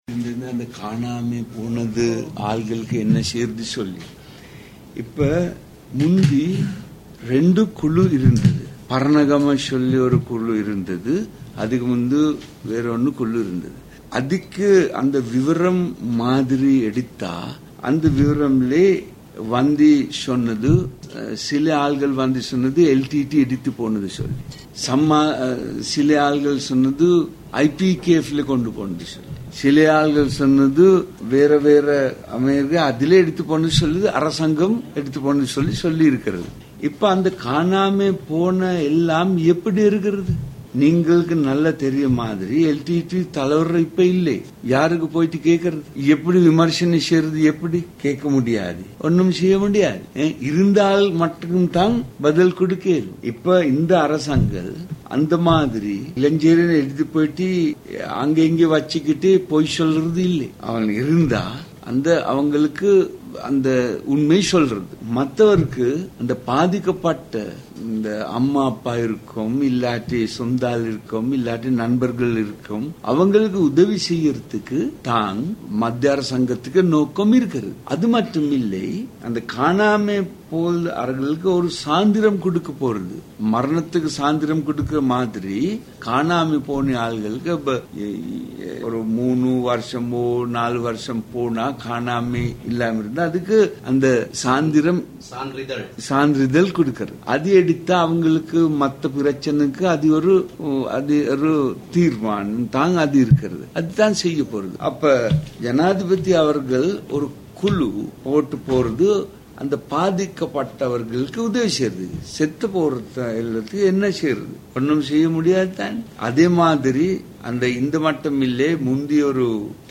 யாழ்ப்பாணத்தில் நடைபெற்ற நிகழ்வொன்றில் வைத்து அவர் இதனைக் கூறியுள்ளார்.